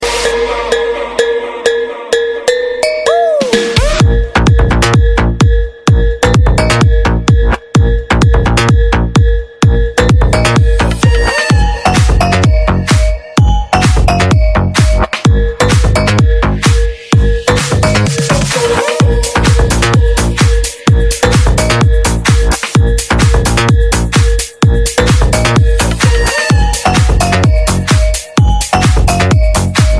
loud